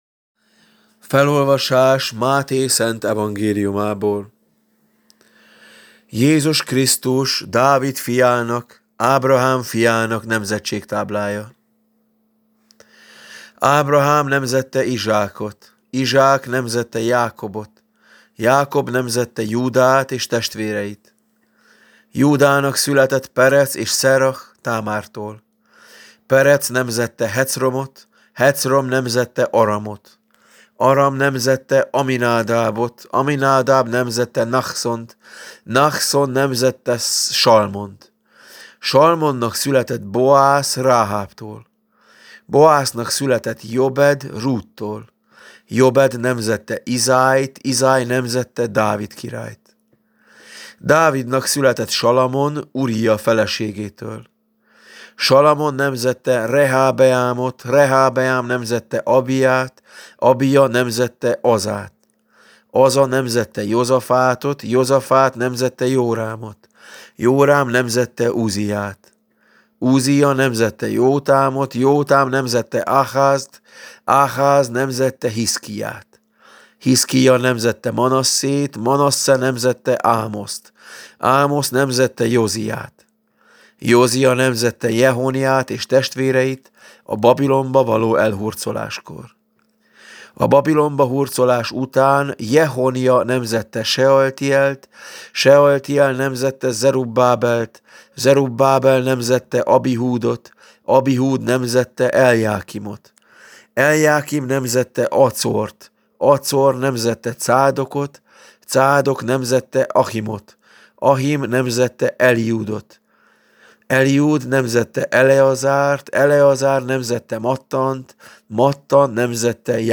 „Természet és Törvény” – Szent Atyák Vasárnapja – 2024. december 22. – Szent György Nagyvértanú templom – Szeged
Evangéliumi olvasmány: